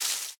leaves5.ogg